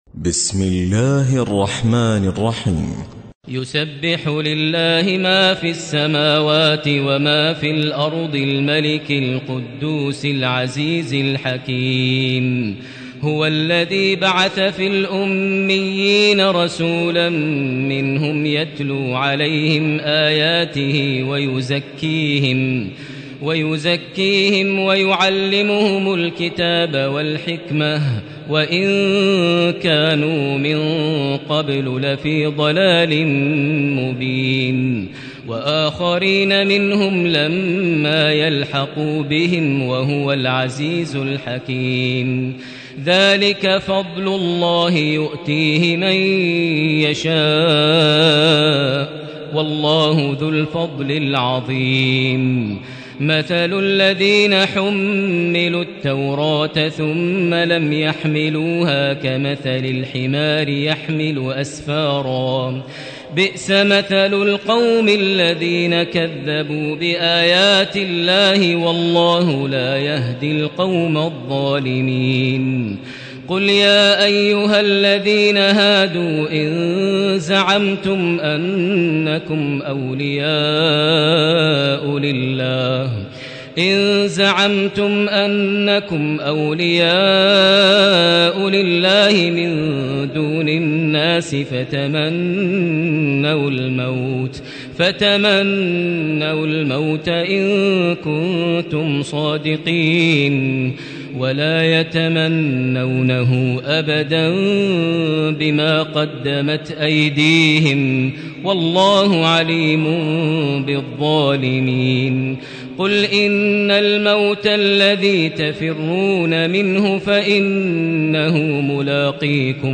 تراويح ليلة 27 رمضان 1437هـ من سورة الجمعة الى التحريم Taraweeh 27 st night Ramadan 1437H from Surah Al-Jumu'a to At-Tahrim > تراويح الحرم المكي عام 1437 🕋 > التراويح - تلاوات الحرمين